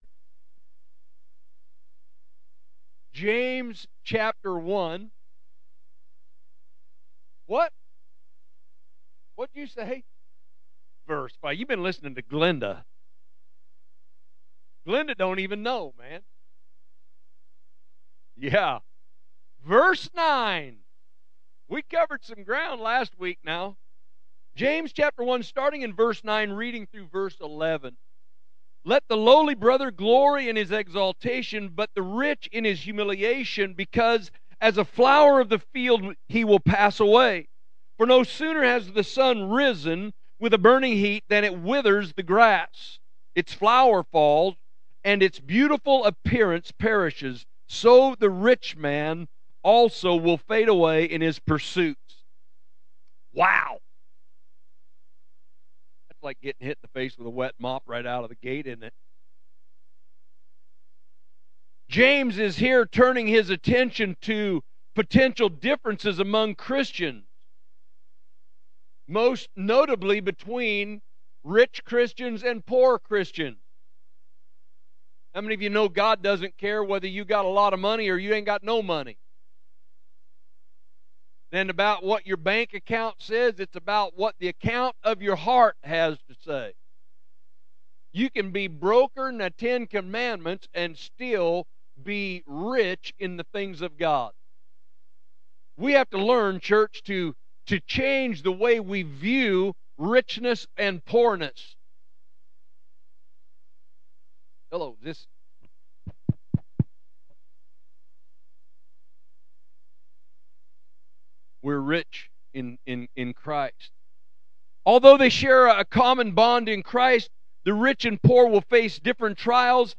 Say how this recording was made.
James Week 8 – Midweek Service March 11, 2026